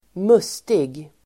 Uttal: [²m'us:tig]